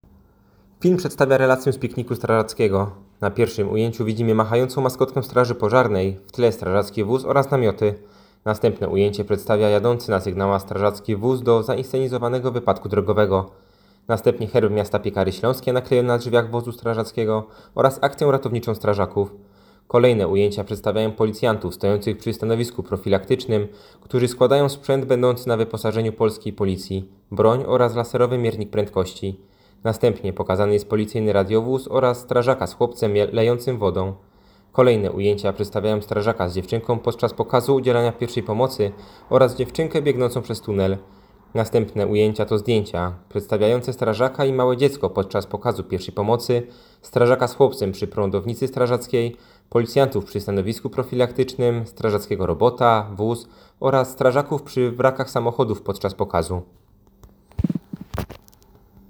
Opis nagrania: Audiodeskrypcja filmu